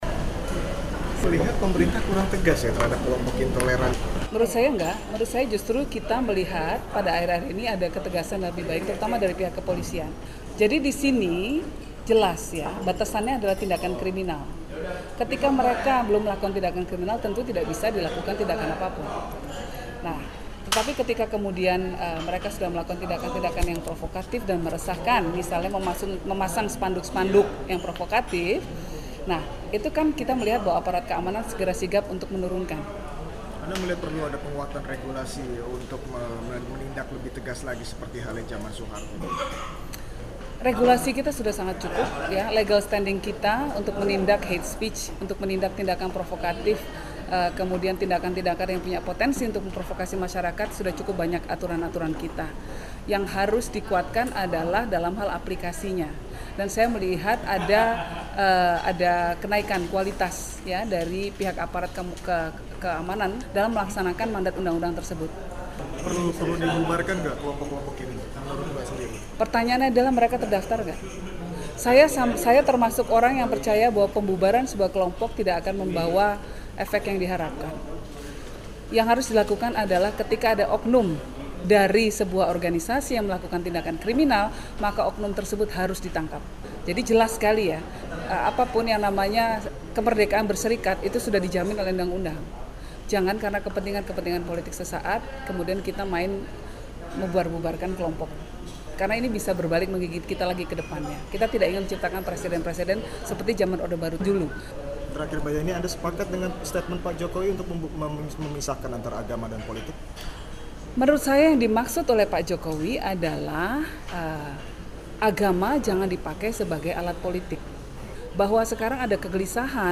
Wawancara Yenny Wahid: Antara Kelompok Intoleran, Politik, dan Ketegasan Pemerintah